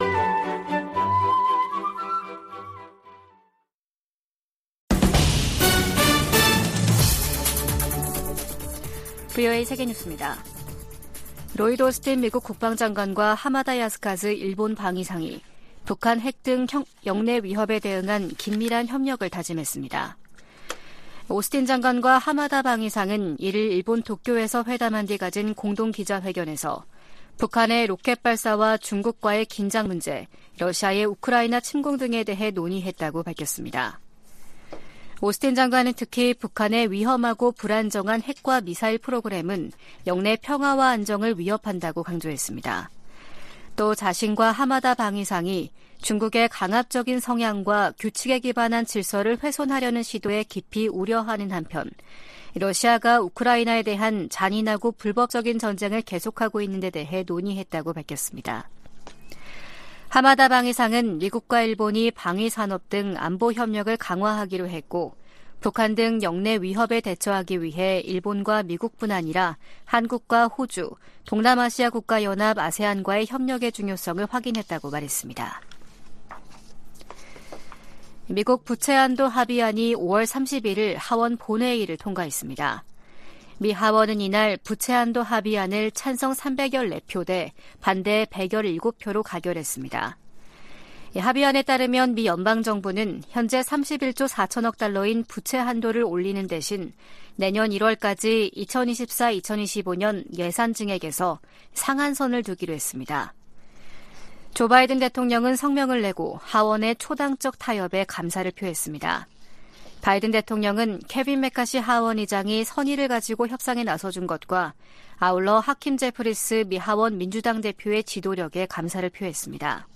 VOA 한국어 아침 뉴스 프로그램 '워싱턴 뉴스 광장' 2023년 6월 2일 방송입니다. 북한은 정찰위성 성공 여부와 관계 없이 군사 능력을 계속 발전시킬 것이라고 백악관 대변인이 말했습니다.